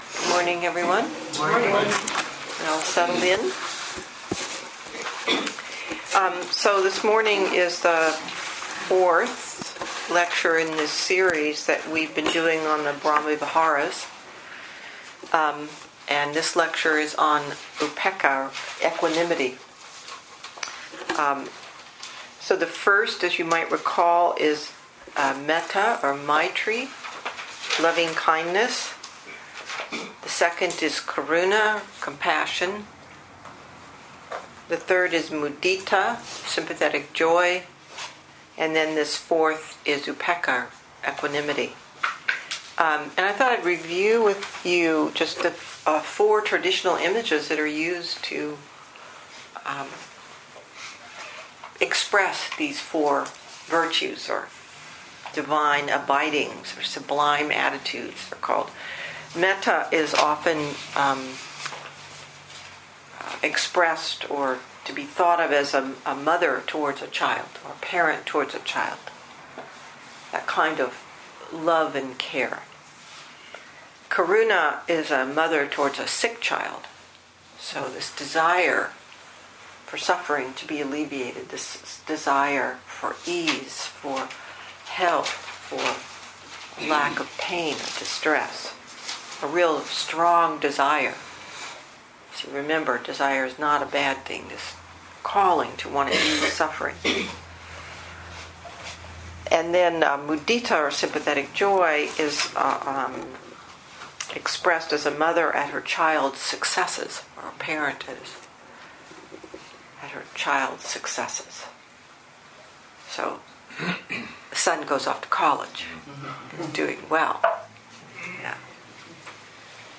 2016 in Dharma Talks